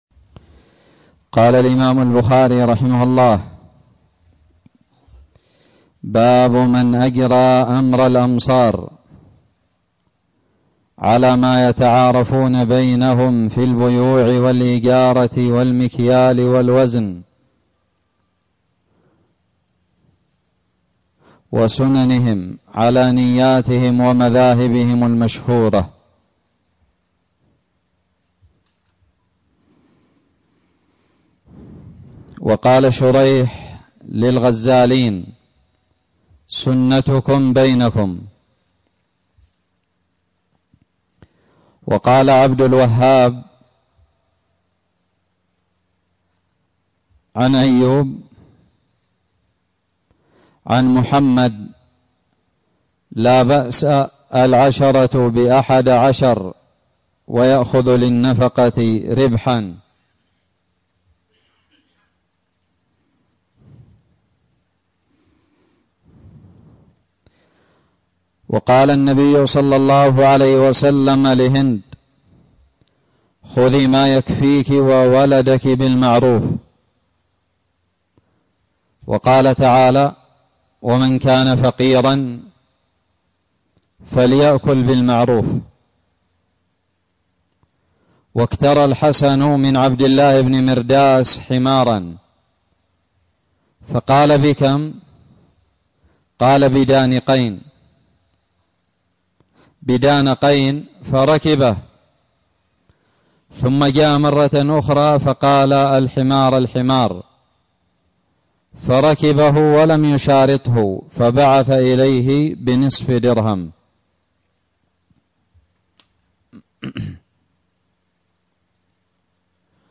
شرح صحيح الإمام البخاري- متجدد
ألقيت بدار الحديث السلفية للعلوم الشرعية بالضالع